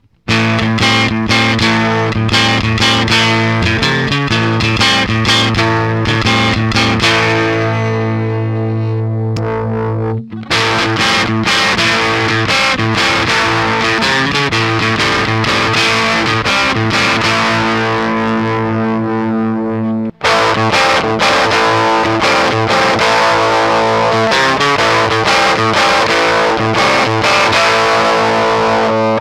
quelques samples pour te faire une idée (sortie line avec un cut des aigus pour simuler rapidement un cab)
EF86drive-fuzz-fuzz_himid_boost.ogg